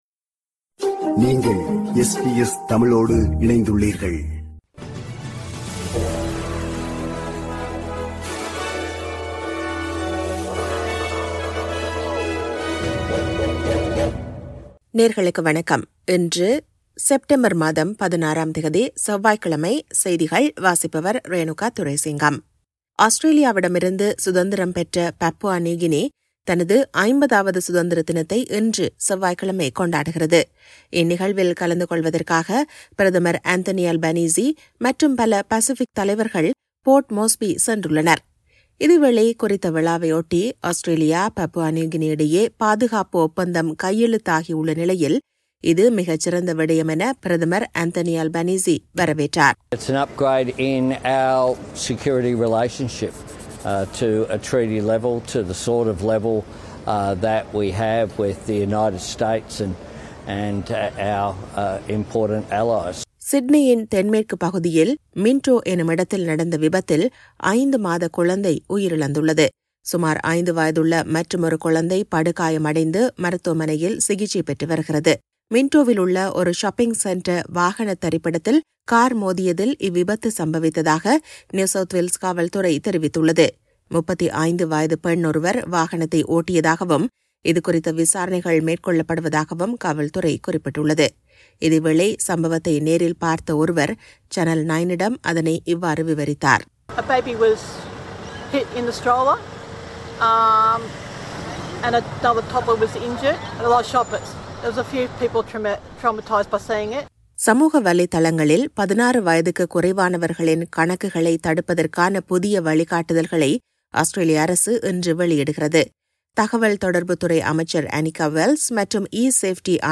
இன்றைய செய்திகள்: 16 செப்டம்பர் 2025 செவ்வாய்க்கிழமை
SBS தமிழ் ஒலிபரப்பின் இன்றைய (செவ்வாய்க்கிழமை 16/09/2025) செய்திகள்.